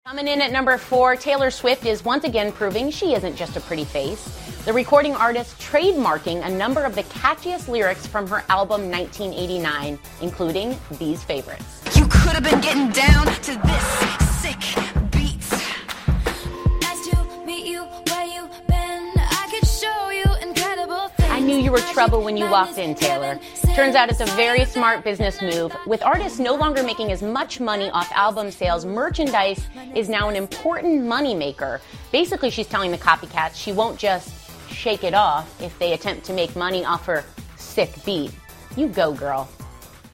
访谈录 泰勒·斯威夫特《1989》 听力文件下载—在线英语听力室